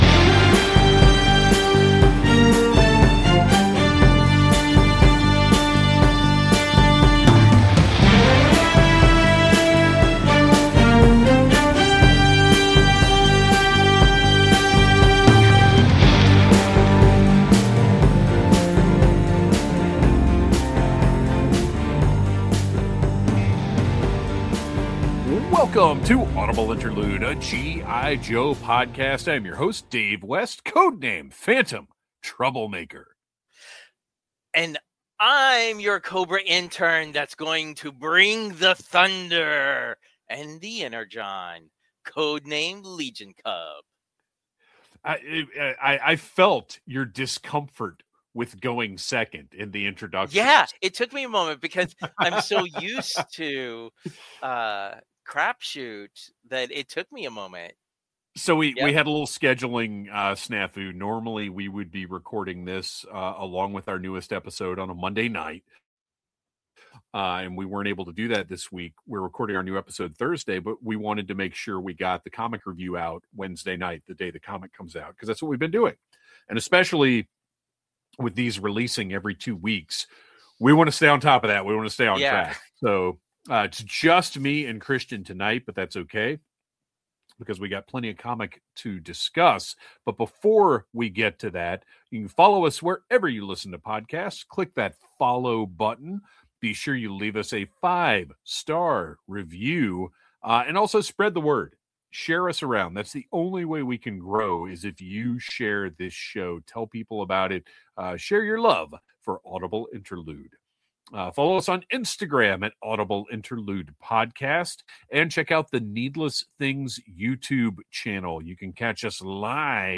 We're recruiting you to listen to this podcast hosted by three lifelong Joe fans.